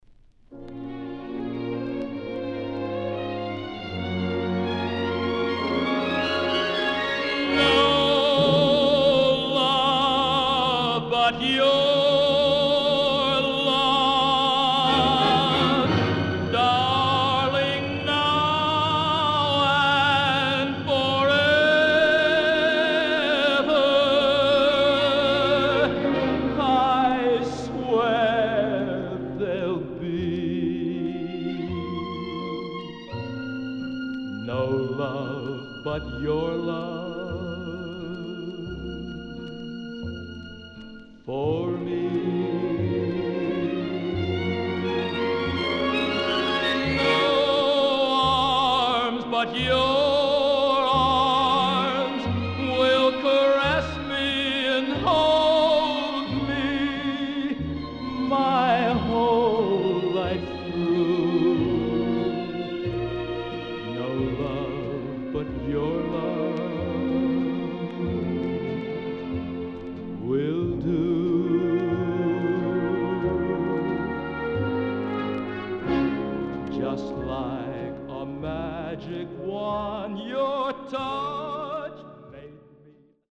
SIDE A:盤質は良好です。